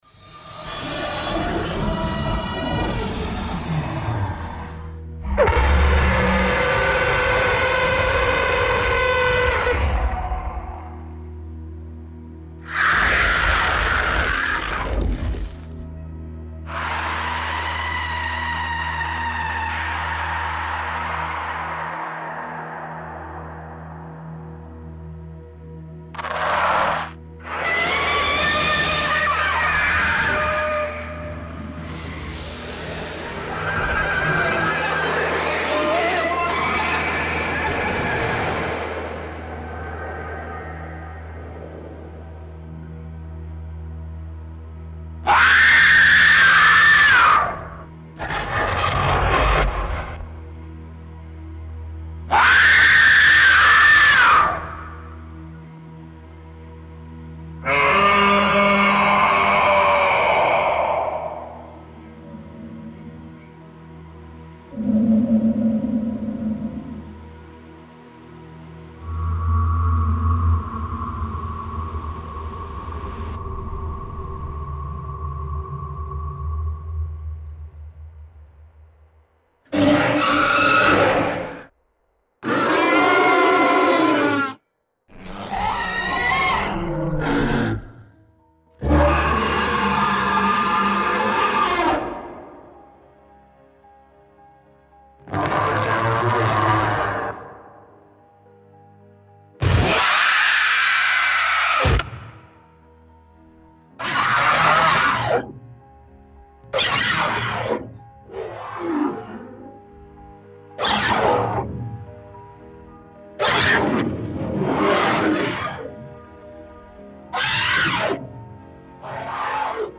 Screaming Monsters | Creatures Sound FX | Unity Asset Store
Screaming Monsters
01 SciFi Monster.mp3